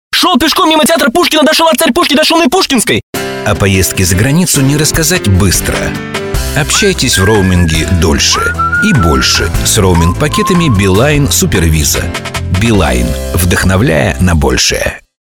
Radio Commercials